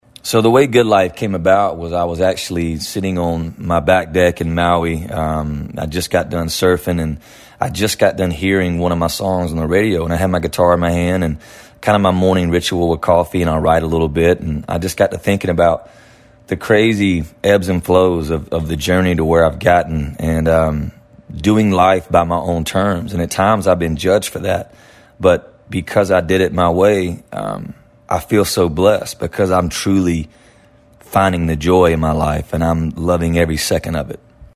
Audio / Kip Moore talks about the inspiration behind his new single, "Good Life."